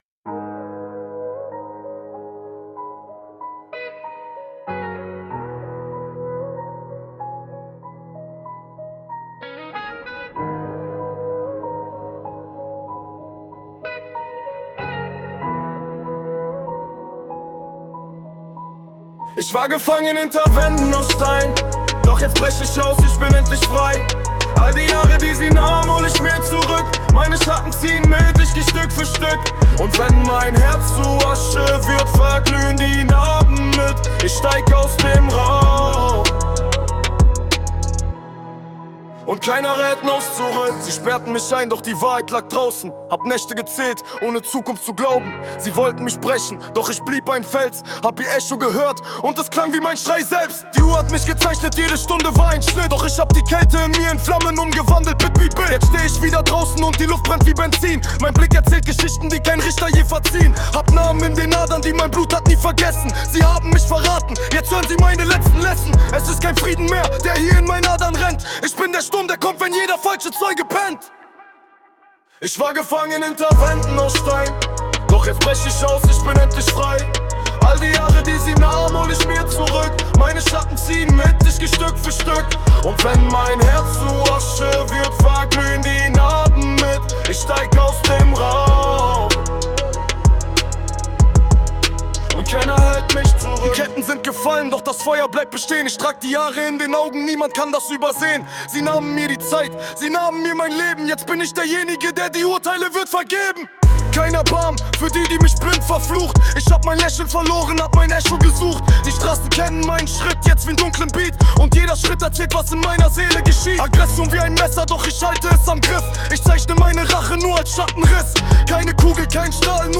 Lyrics und Beats aus dem Innern eines Ex-Knastis Über mich Schon bevor ich im April 2027 offiziell frei bin, meine Bewährungsstrafe also vorüber ist, mache ich Musik! Ich schreibe meine Texte aus eigenen Erfahrungen und schmücke diese durch Erlebnisse aus.